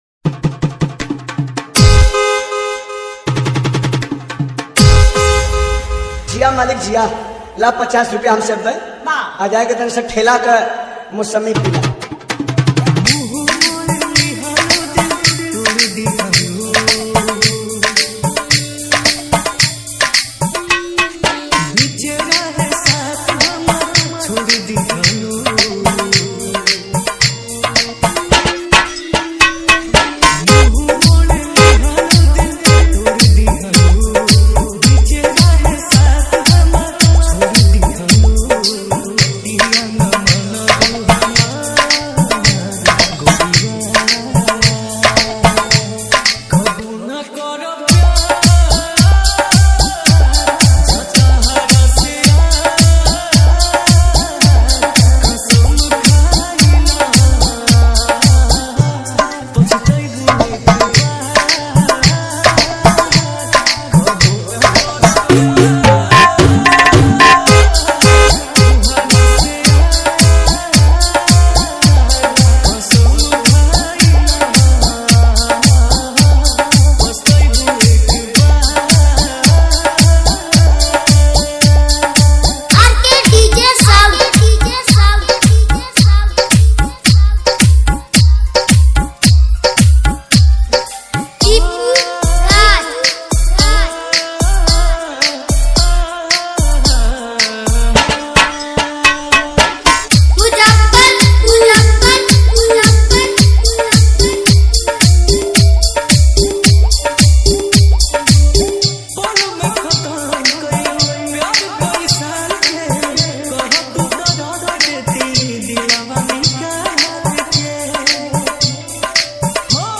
Bhojpuri Love DJ Remix